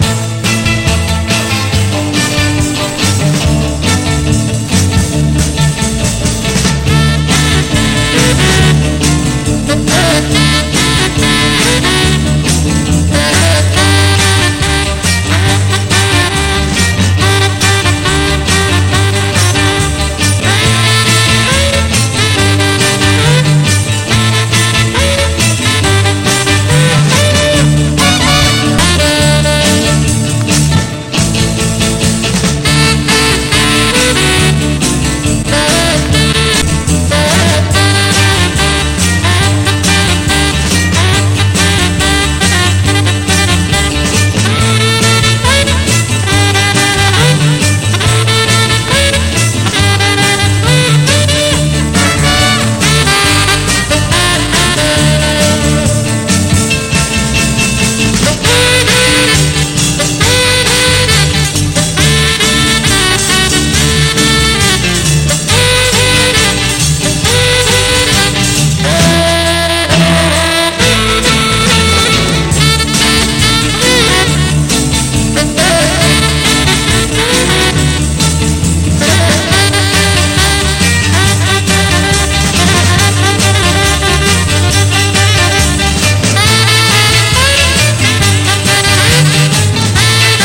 女声コーラス入りのファンキー・フレンチ・ボッサ！